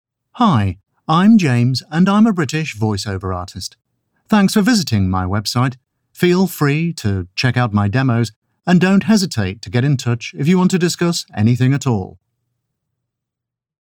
Hire a British male voiceover artist with real experience in commercial voiceover, audiobooks and documentary narration.